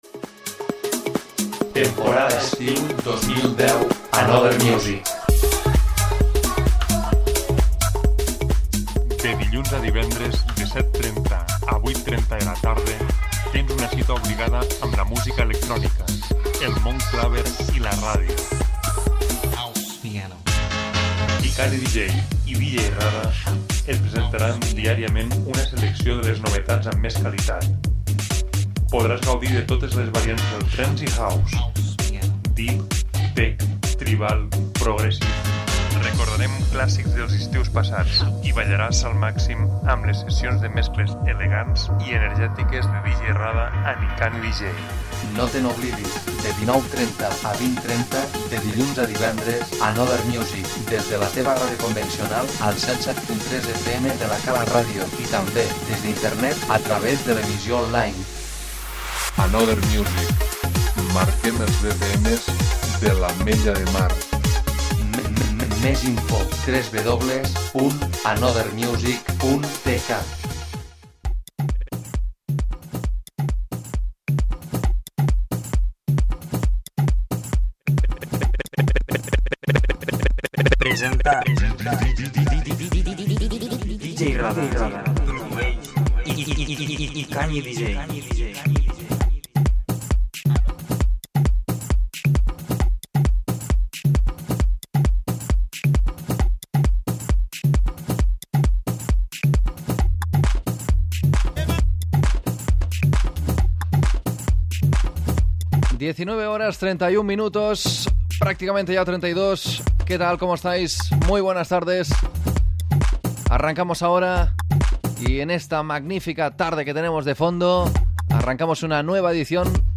a nivell progressive-house i tech-house.